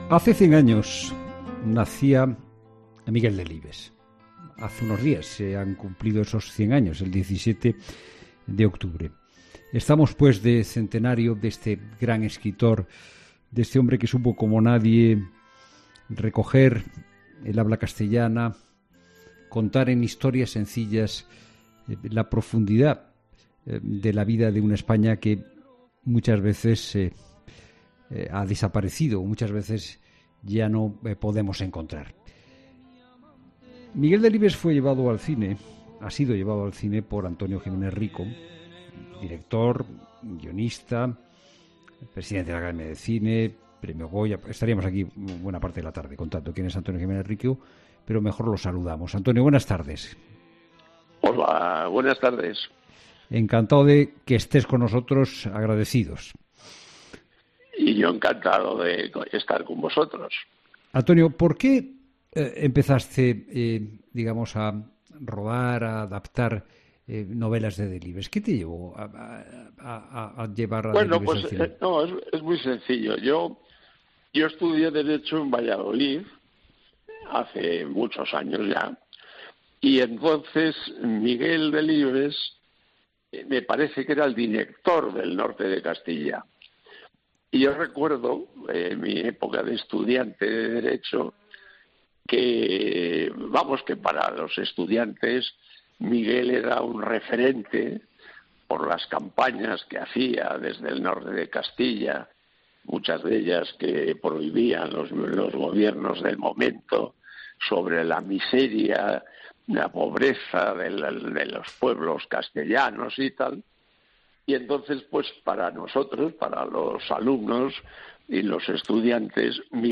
AUDIO: El director de cine Antonio Giménez-Rico, quien llevó a la gran pantalla algunas novelas del escritor vallisoletano, charla con Fernando de...